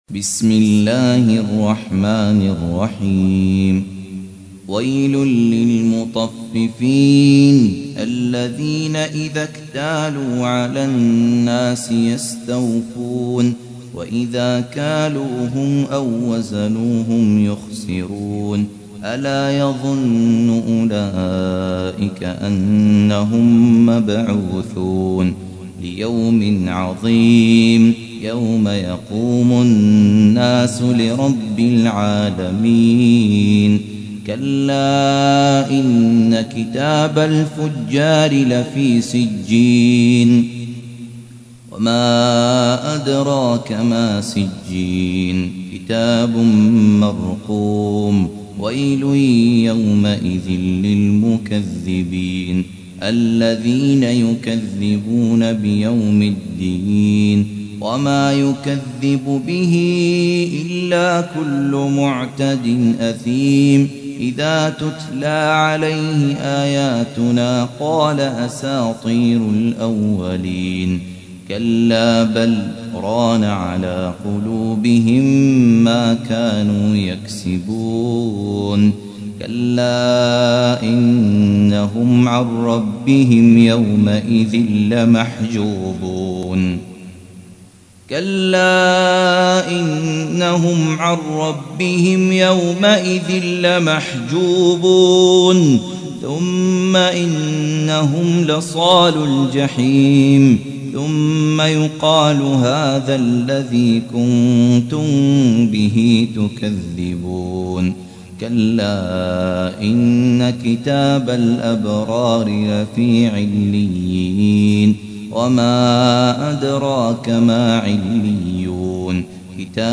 تحميل : 83. سورة المطففين / القارئ خالد عبد الكافي / القرآن الكريم / موقع يا حسين